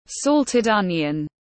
Salted onion / ˈsɒl.tɪd ˈpɪk.əl/